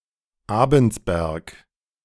Abensberg (German pronunciation: [ˈaːbənsˌbɛʁk]
De-Abensberg.ogg.mp3